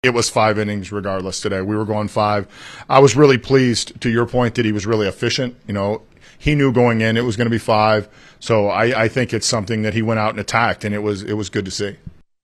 Shelton says the Pirates are limiting the innings for Skenes, who has never pitched as much as he has this season.